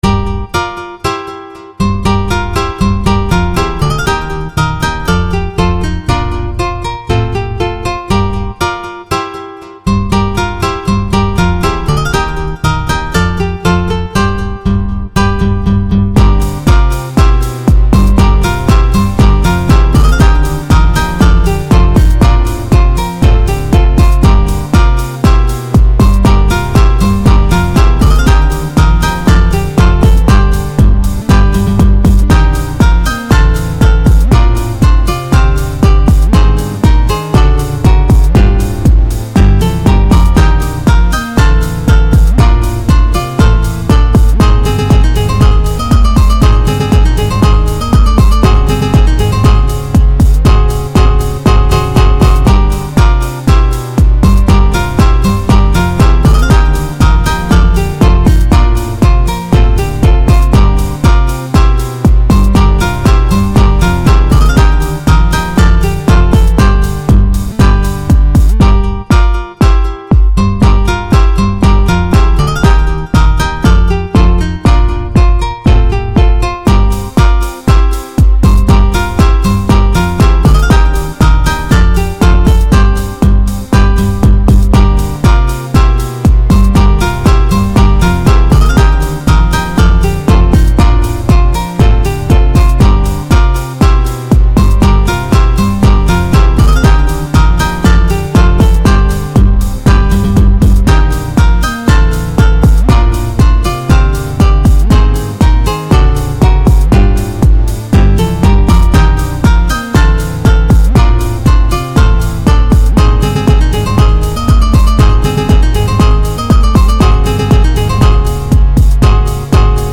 (VSTiMIX、BPM119) house?